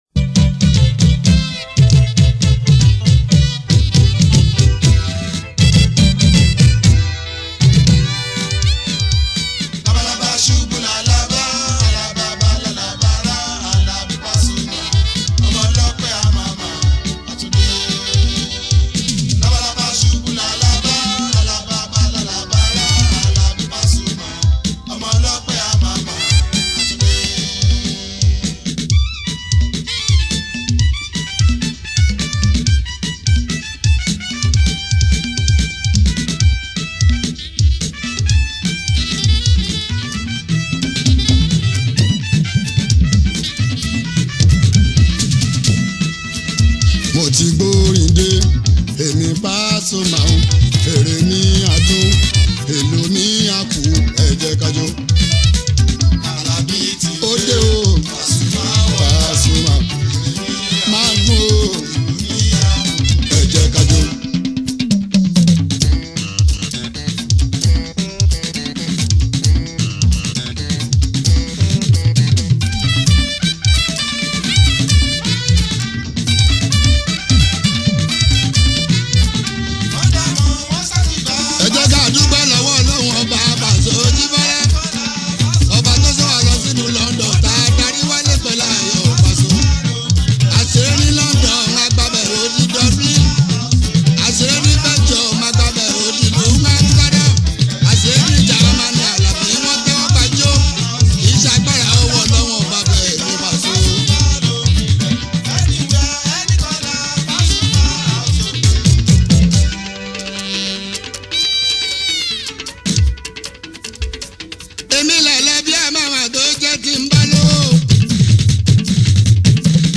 Download and stream Mixtape Below